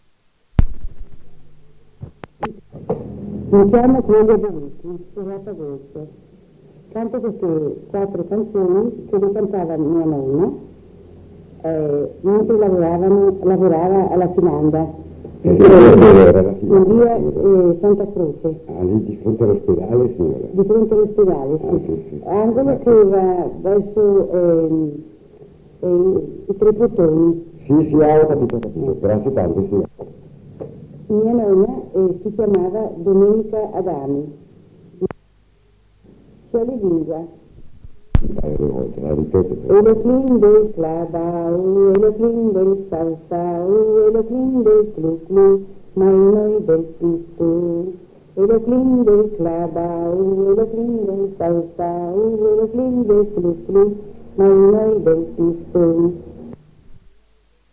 7 giugno 1978». 1975. 1 bobina di nastro magnetico.